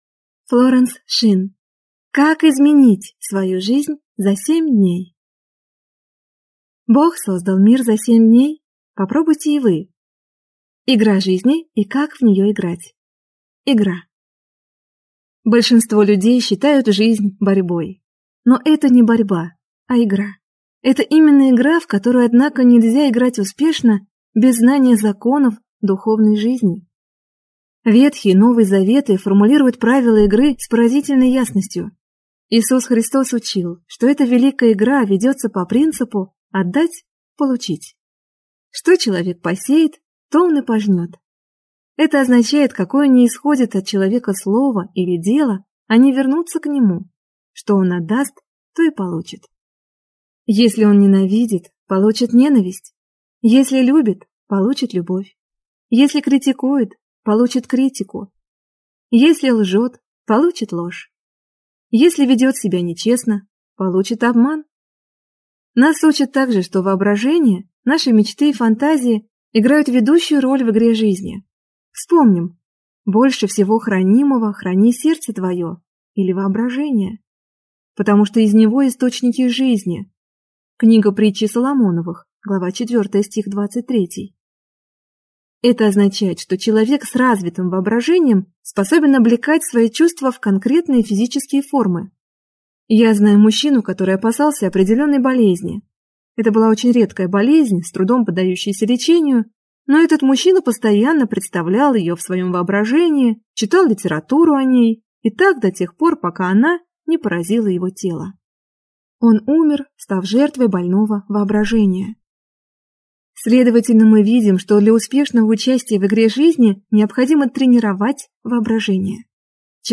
Аудиокнига Как изменить свою жизнь за 7 дней | Библиотека аудиокниг